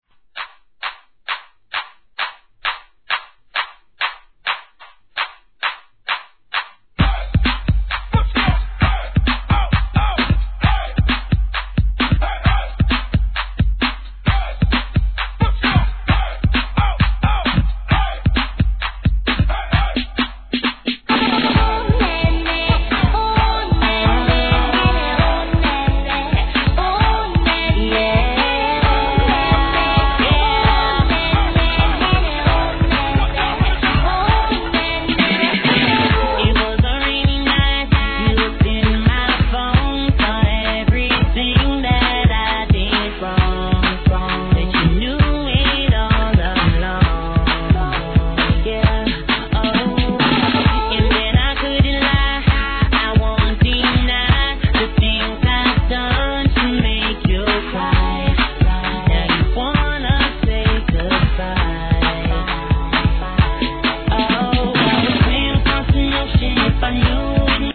HIP HOP/R&B
BPM132